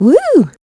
Ripine-Vox_Happy4.wav